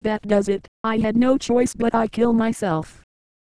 Worms speechbanks
ohdear.wav